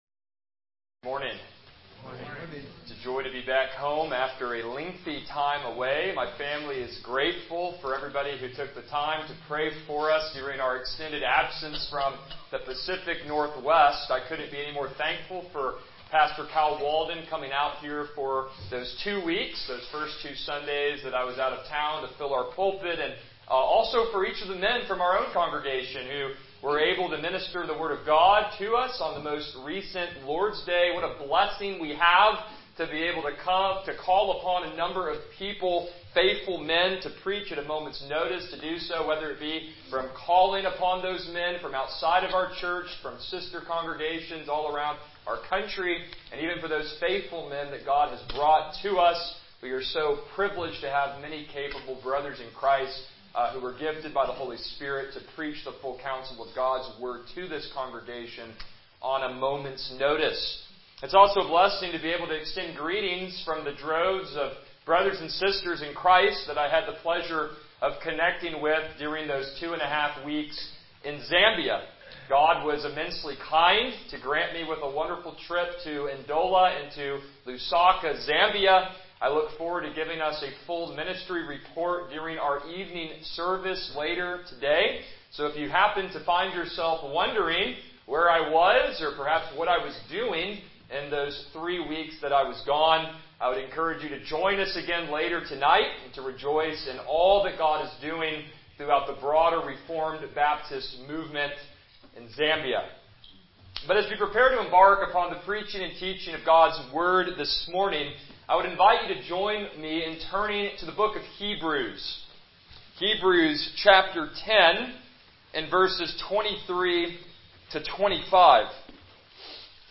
Passage: Hebrews 10:23-25 Service Type: Morning Worship